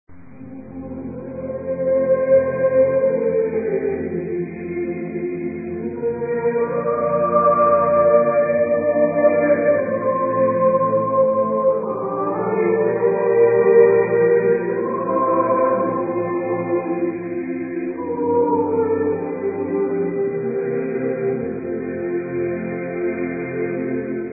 SSAATTBB (8 voix mixtes) ; Partition complète.
Folklore.